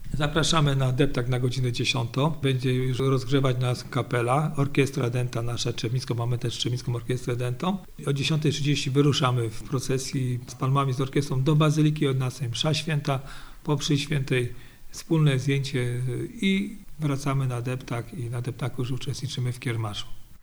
Na obchody Niedzieli Palmowej w Trzebnicy zaprasza burmistrz gminy, Marek Długozima.